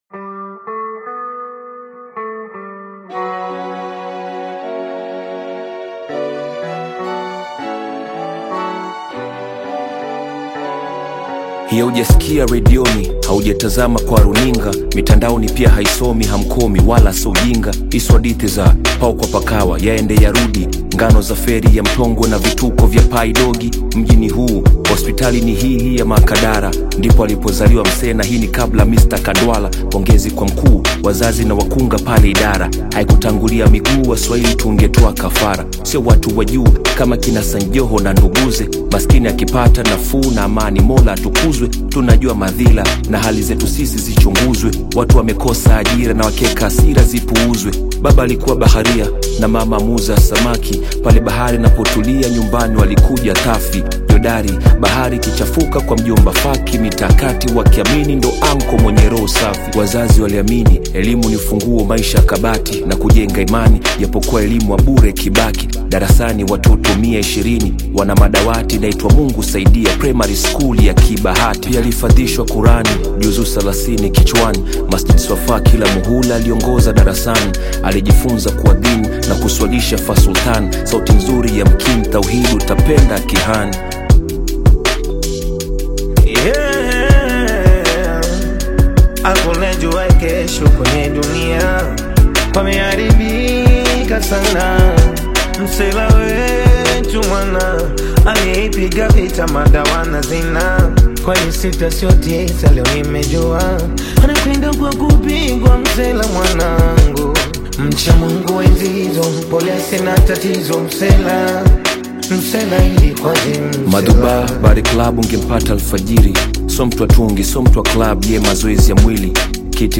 AudioHip Hop